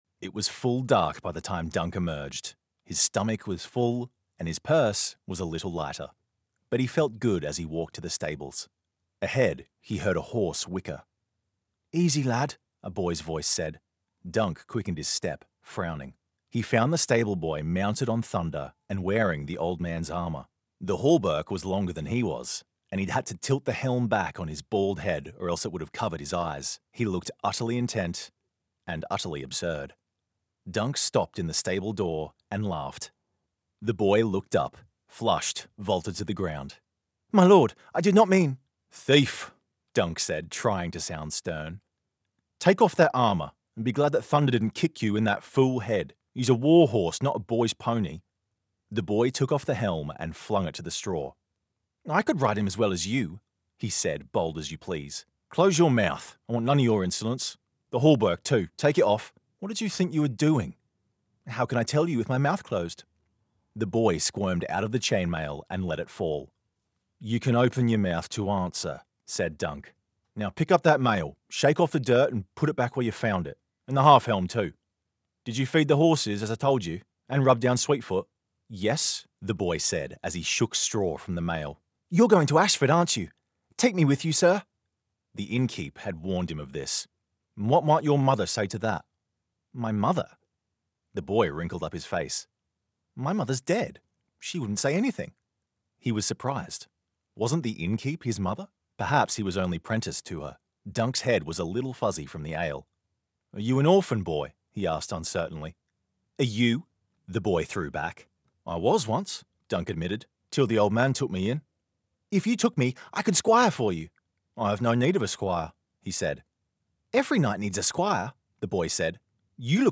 Audio Book Voice Over Narrators
Yng Adult (18-29) | Adult (30-50)